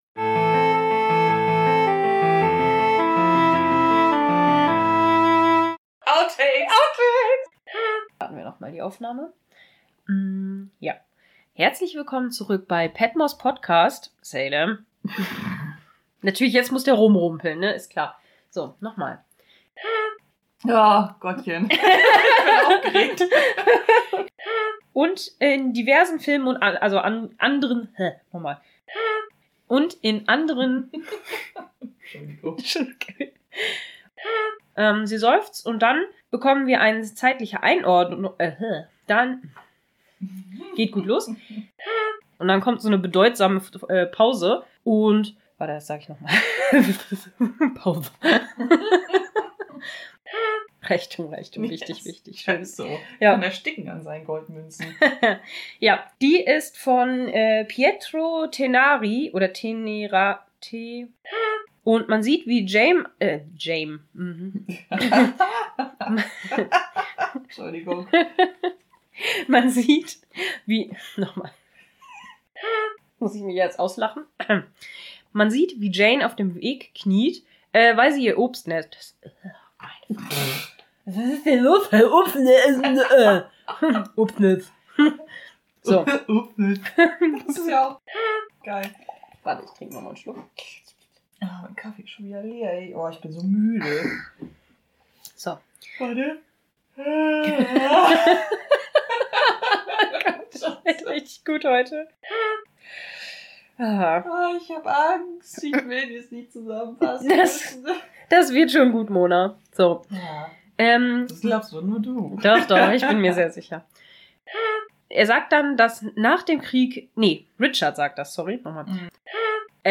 Es gibt wieder viel Gelache und Versprecher.